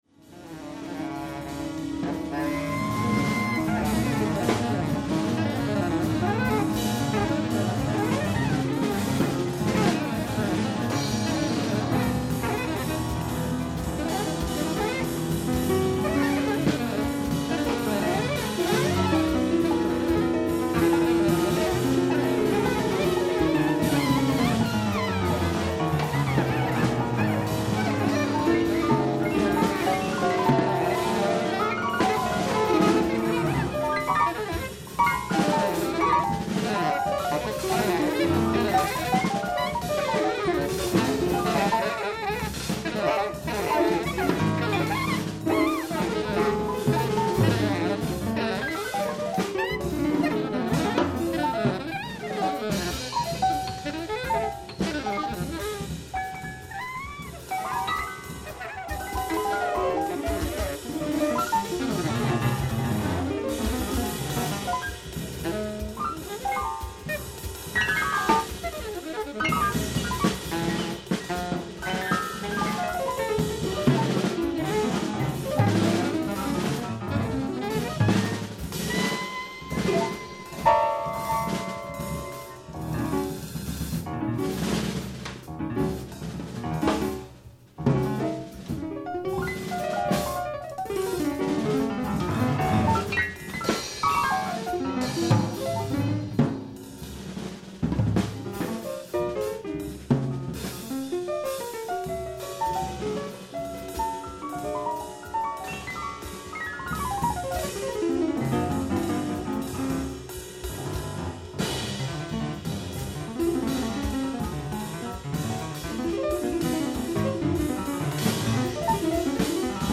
#1-2 recorded in concert at Kägelbanan,